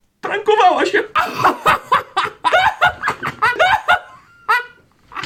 Play Rudy śmiech - SoundBoardGuy